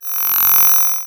BadTransmission1.wav